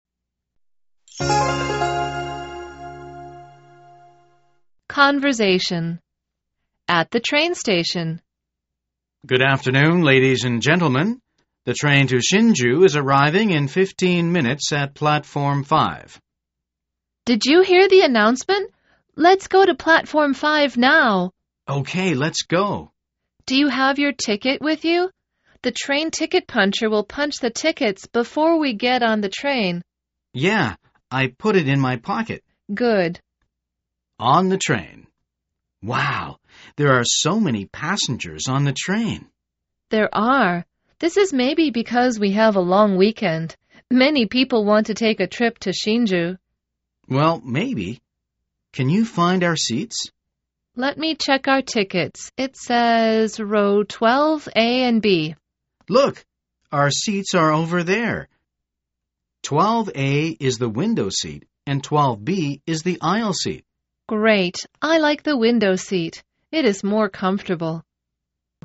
口语会话